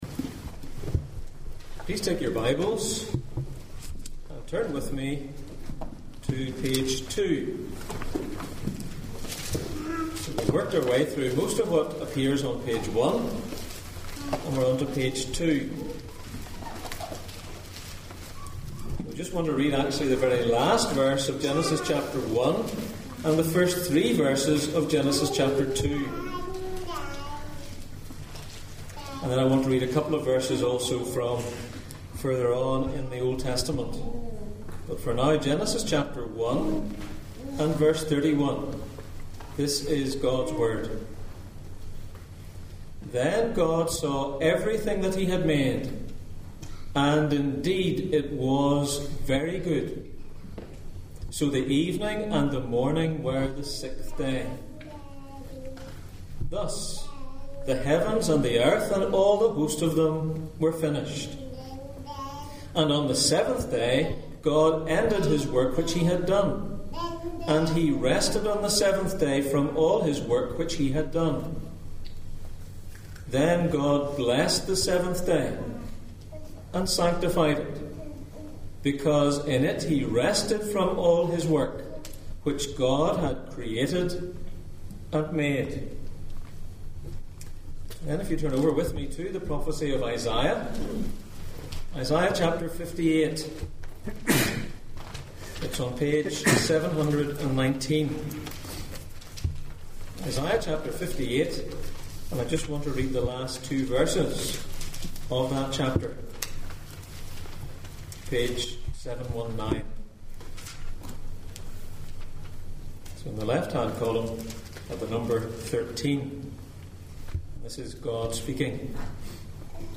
Passage: Genesis 1:31-2:3, Isaiah 58:13-14, Isaiah 53:11, Exodus 20:8-11, Deuteronomy 5:12-15 Service Type: Sunday Morning